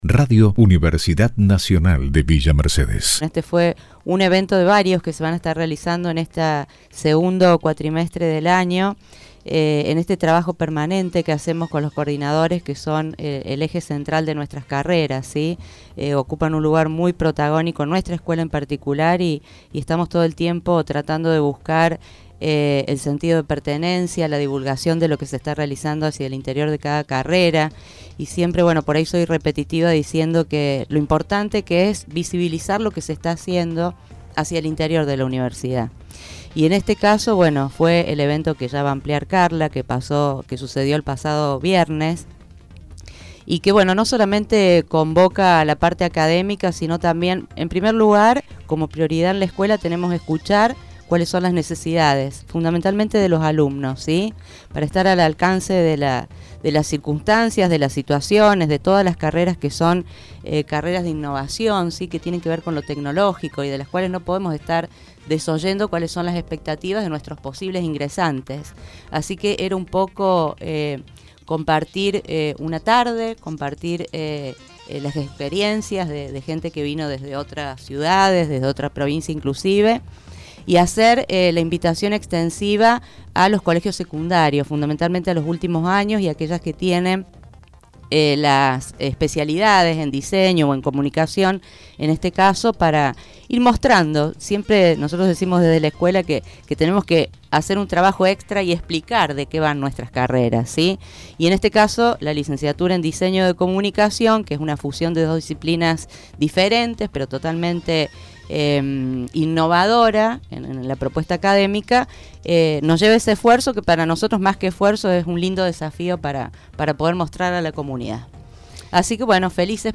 En diálogo con Radio UNViMe 93.7 MHz, las funcionarias destacaron el compromiso y el esfuerzo de todos quienes participaron en la organización, de los expositores, y el trabajo conjunto con la Municipalidad de Villa Mercedes, disponiendo el lugar y la logística para el desarrollo de la actividad.